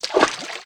STEPS Water, Walk 07.wav